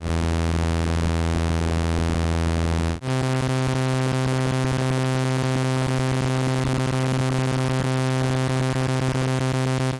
Stimmtöne Arkadische Botschaften I mp3
micro_guitar6VIII.mp3